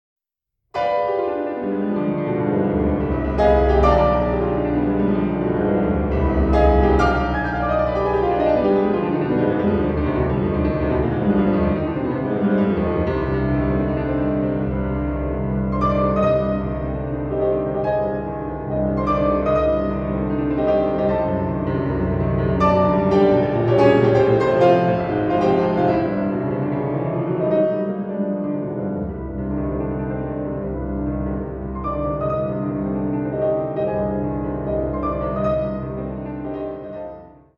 Classical, Keyboard